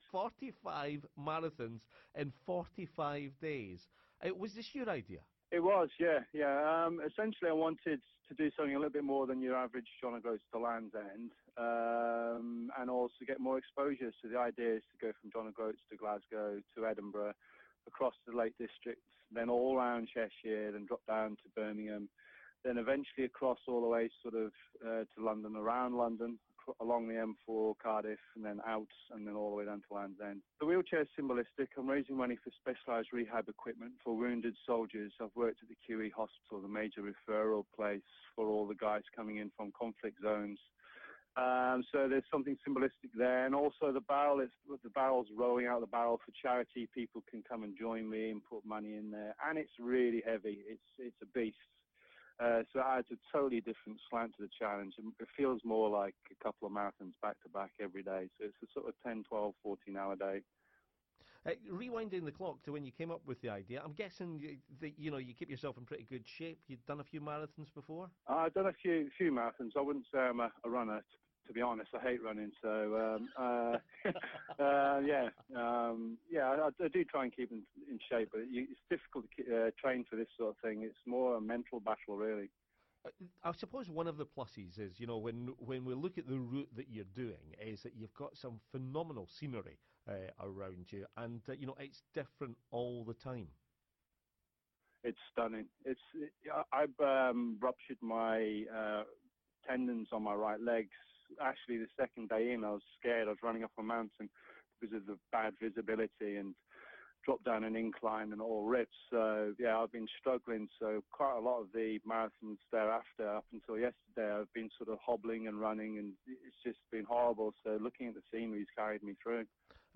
Interview with BFBS Scotland
BFBS-Interview-cut.mp3